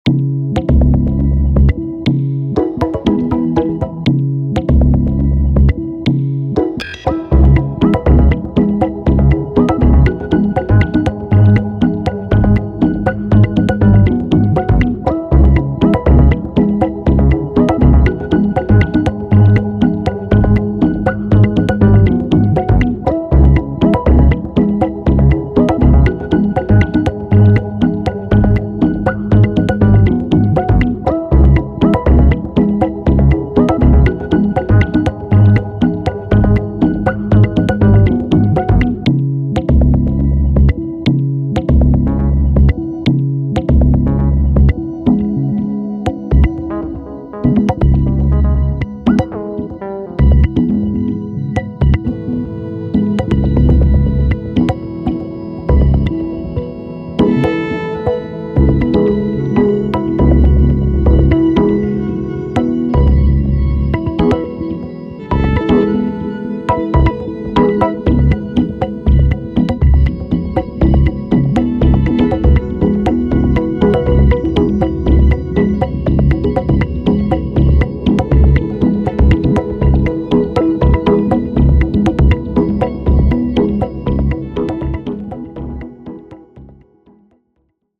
Having a bit of fun cutting up some of the SubH/QPAS stuff in Live and looping some sections, never know where to stop when i start doing this though